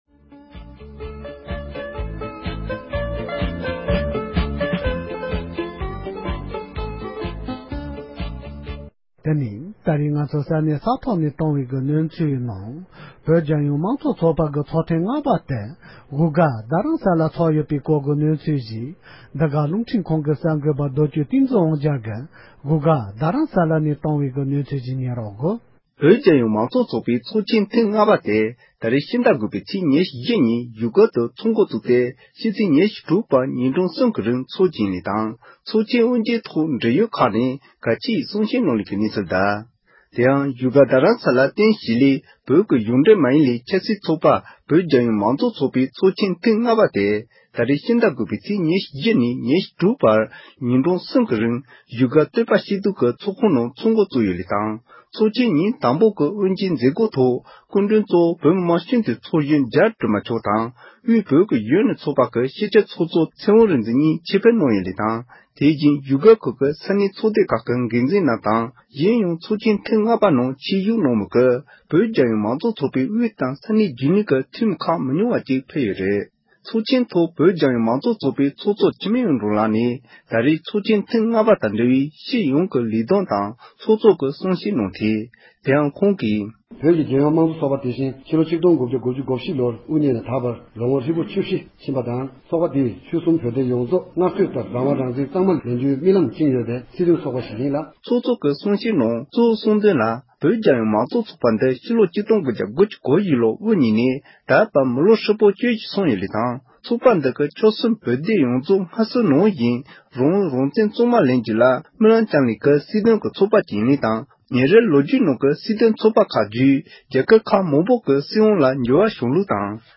གསར་འགྱུར་ལ་གསན་རོགས་གནང་།